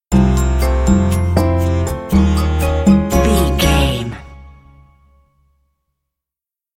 Aeolian/Minor
piano
percussion
flute
silly
circus
goofy
comical
cheerful
perky
Light hearted
quirky